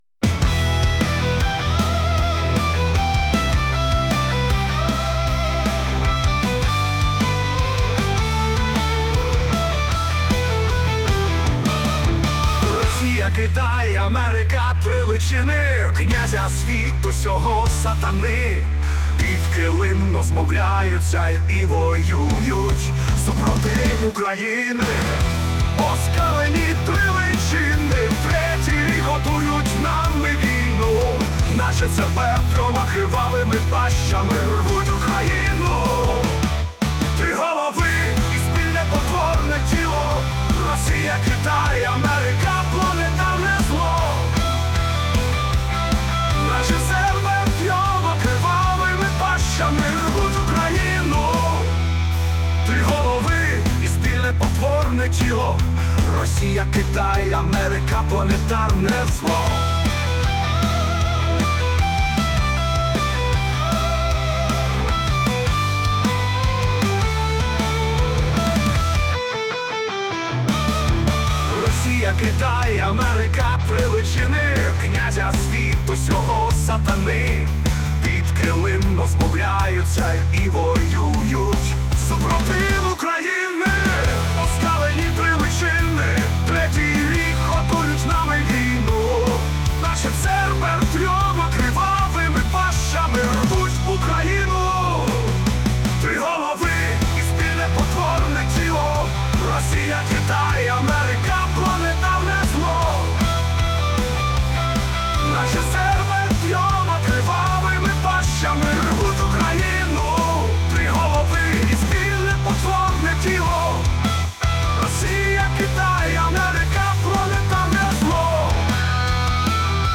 ЦЕРБЕР ( в стилі хард-рок)
ЦЕРБЕР мій зазвучав отак в стилі хард-року)